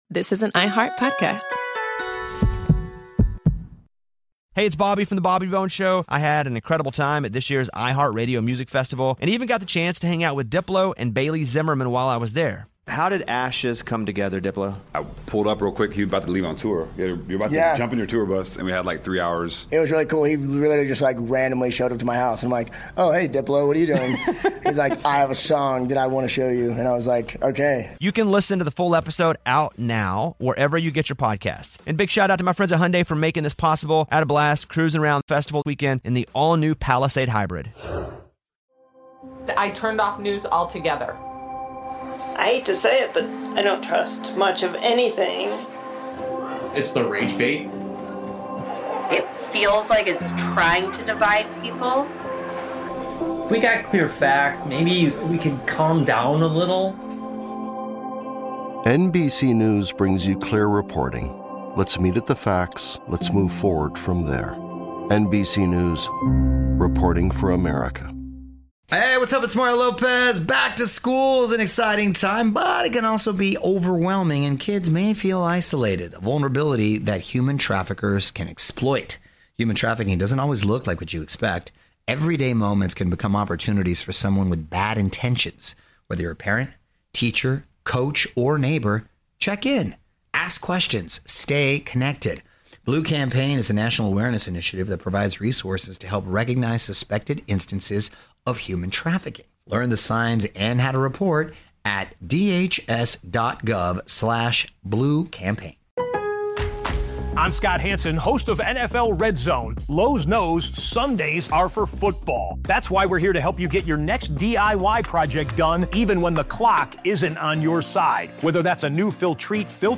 In this episode, we feature Stephen Ambrose in his own words, sharing how D-Day took shape: from the first sketches of landing craft to the final hours before dawn on June 6, 1944.